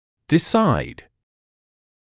[dɪ’saɪd][ди’сайд]решать, принимать решение